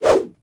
footswing9.ogg